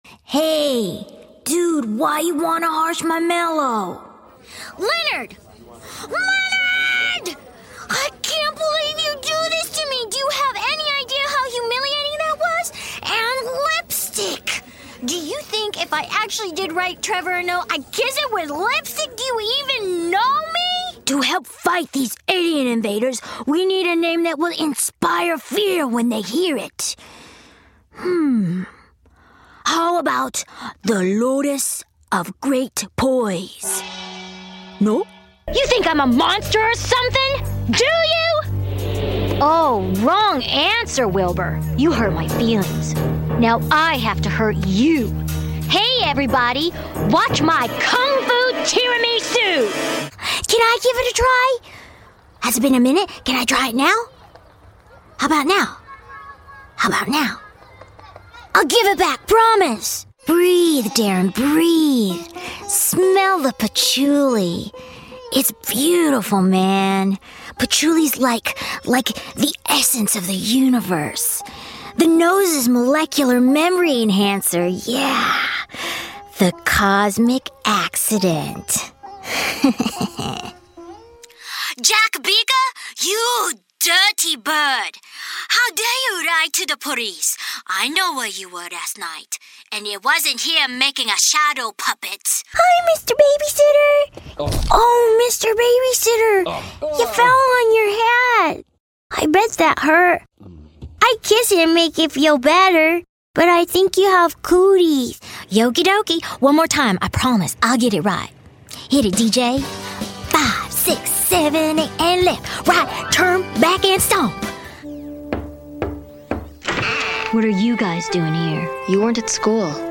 voiceover : animation